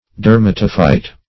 Search Result for " dermatophyte" : The Collaborative International Dictionary of English v.0.48: Dermatophyte \Der*mat"o*phyte\ (d[~e]r*m[a^]t"[-o]*f[imac]t or d[~e]r"m[.a]*t[-o]*f[imac]t), n. [Gr. de`rma, -atos, skin + fyto`n plant.]
dermatophyte.mp3